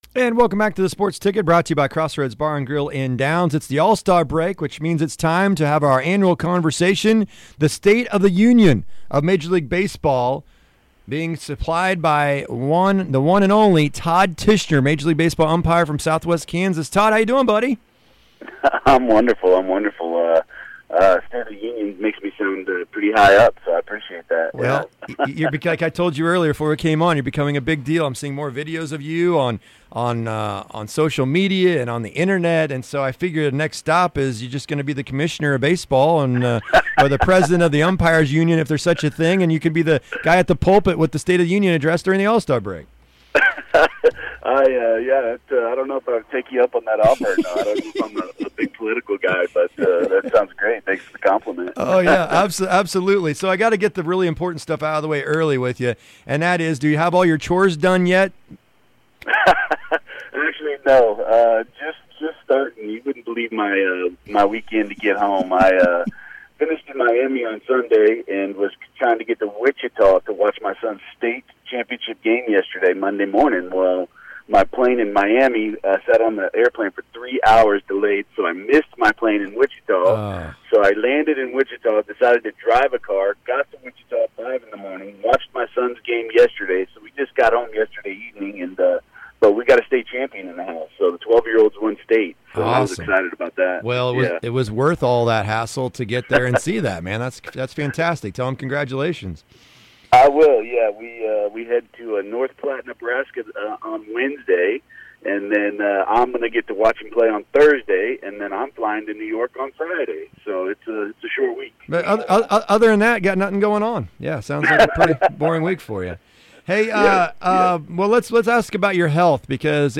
joins us for our annual All-Star Break chat. He shares with us how the game is different from when he started in the bigs, instant replay, behind the scenes of what life for an umpire is like, and shares his best streaker stories, not of himself, of course!!!